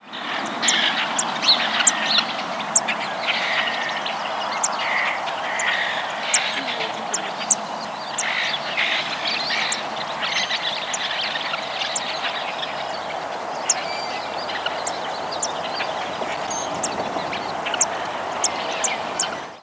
Lark Sparrow Chondestes grammacus
Flight call description A abrupt, squeaky "psyp".
Bird in short flight.
Similar species Similar to Black-throated Blue and Wilson's Warblers but sweeter.